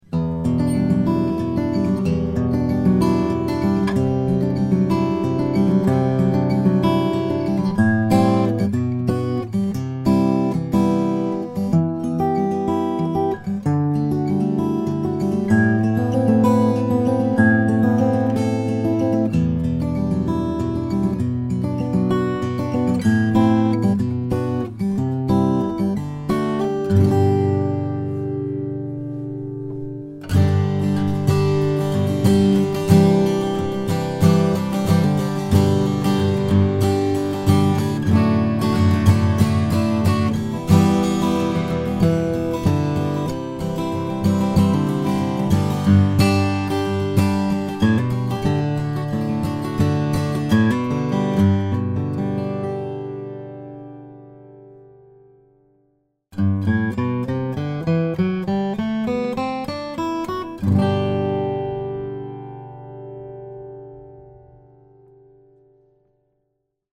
1998 Breedlove CMW Walnut/Sitka - Dream Guitars